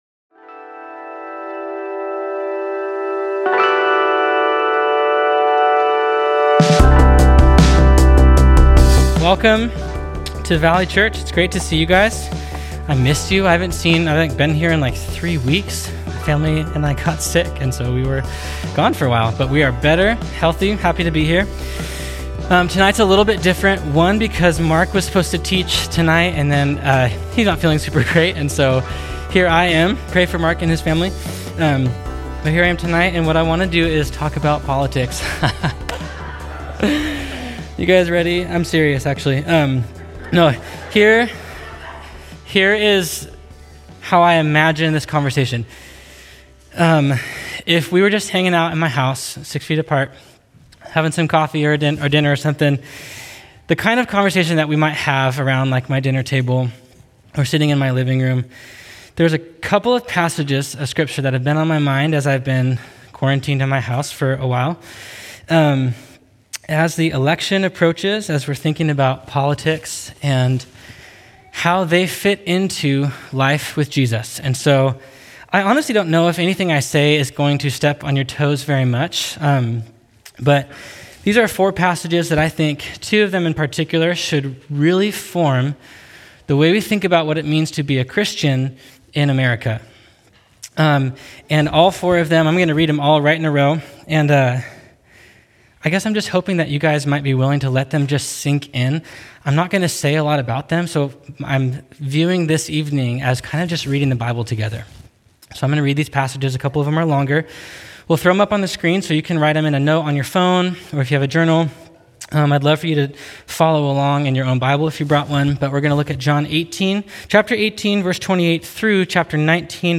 Scripture Reading: John 17:9-19:16, 1 Peter 2:1-17